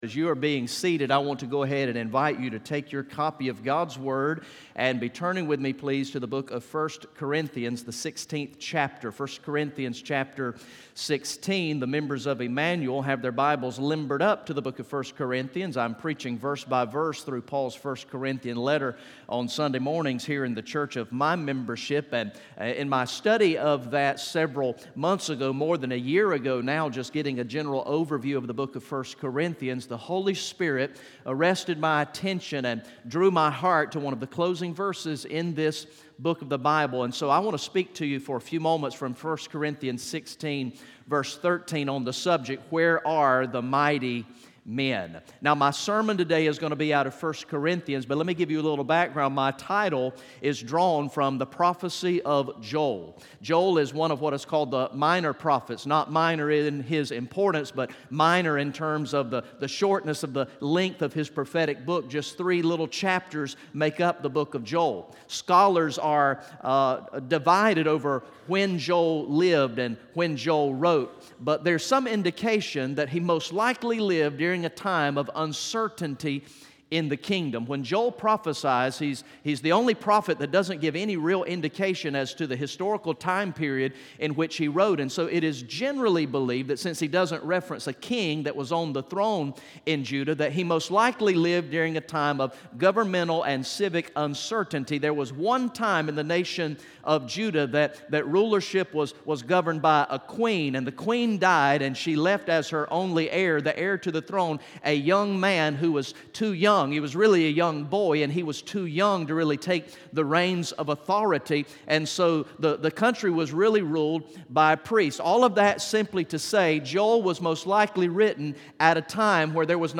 From the Real Momentum conference on Saturday, August 18, 2018